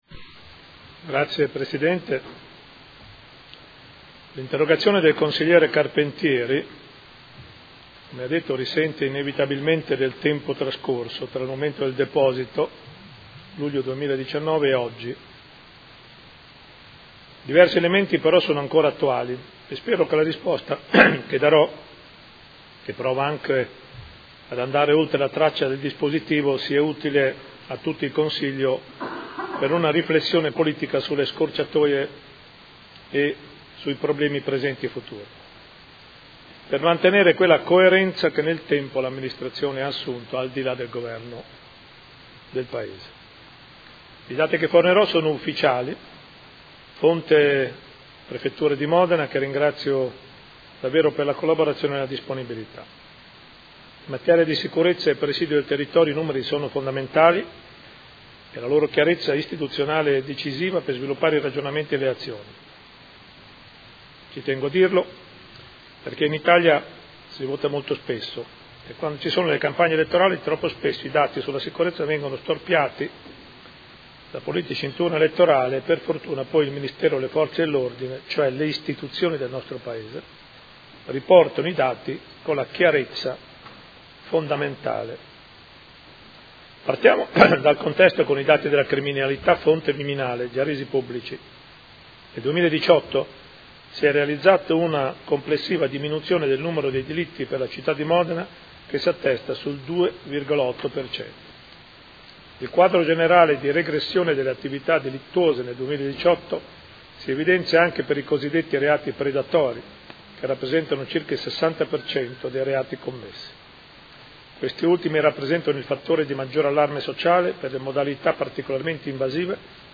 Sindaco